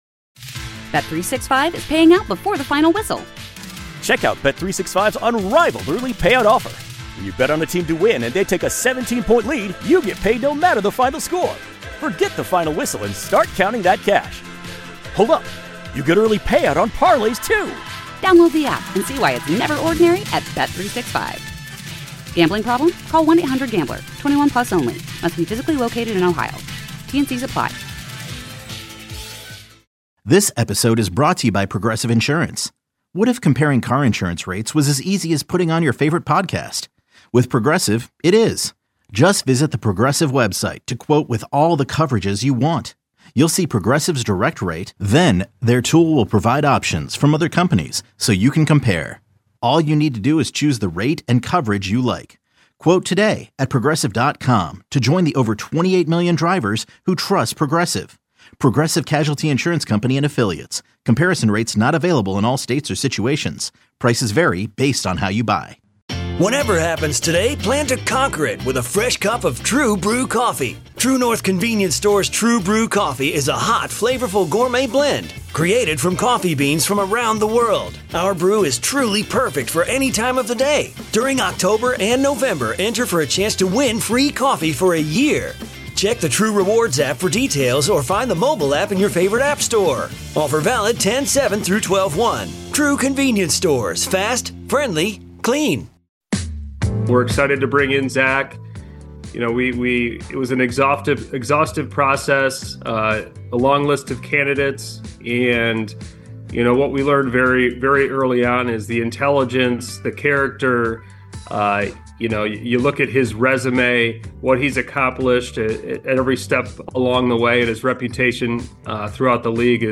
interview, Chin Music (Hour 2)